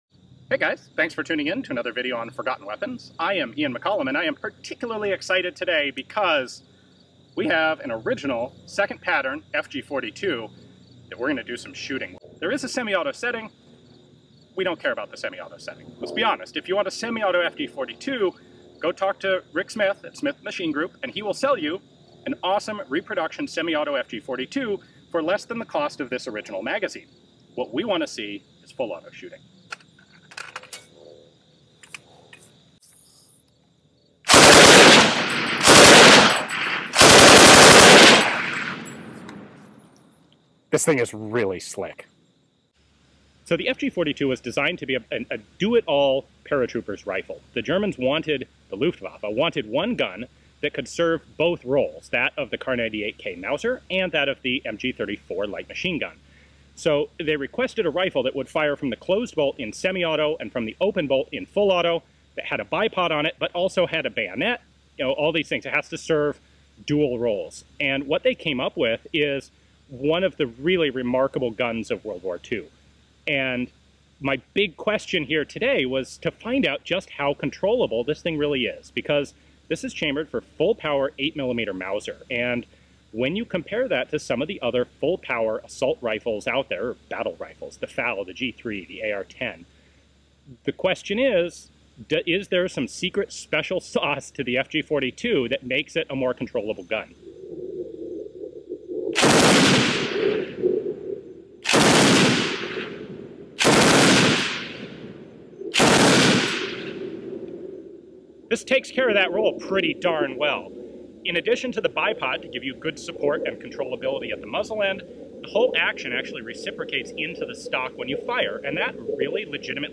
Sounds real GOOD - like a proper gun should.